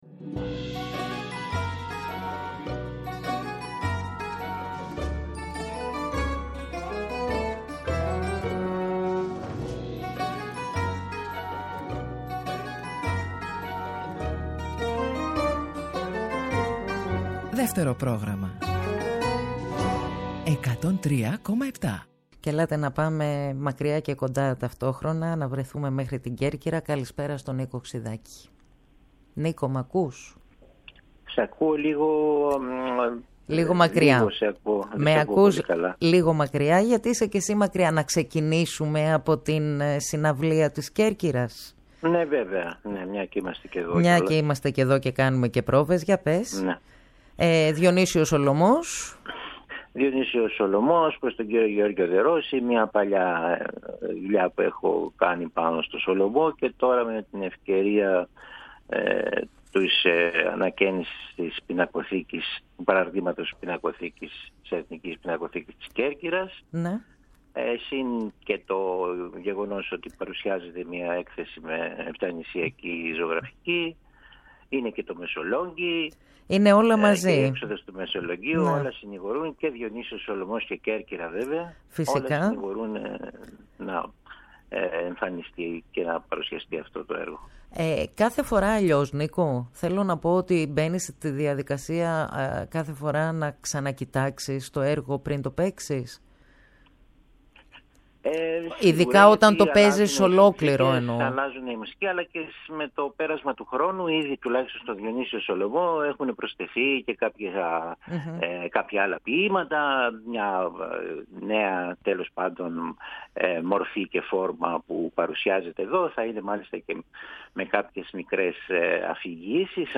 Με τον συνθέτη Νίκο Ξυδάκη, συνομίλησε τηλεφωνικά